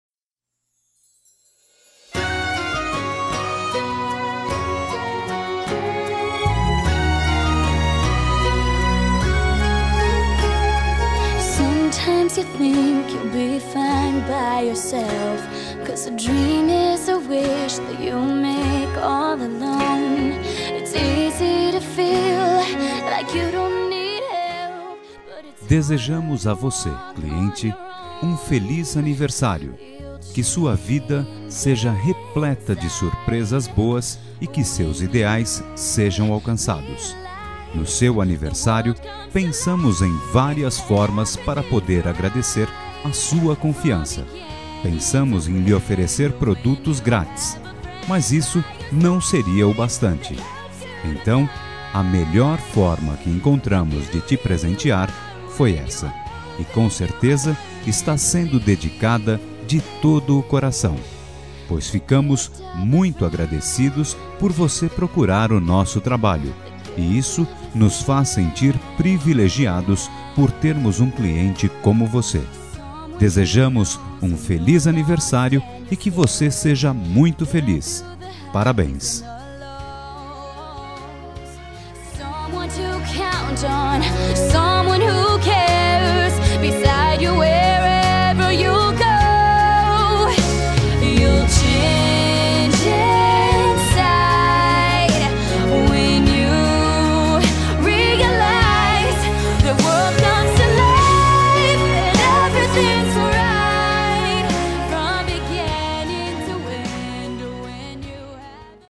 ANIVERSÁRIO CLIENTE
Voz Masculina
Código: 70403 – Música: Gift Of a Friend – Artista: Demi Lovato